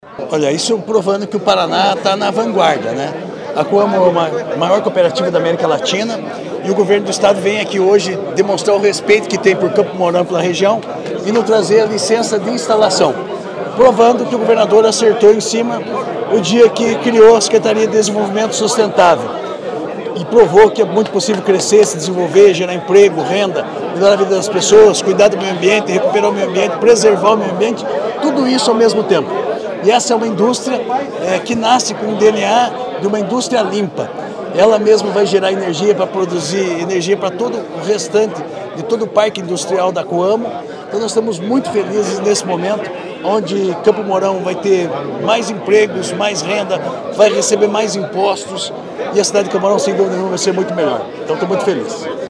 Sonora do secretário Estadual da Agricultura e Abastecimento, Marcio Nunes, sobre a entrega de licença para a fábrica de etanol de milho da Coamo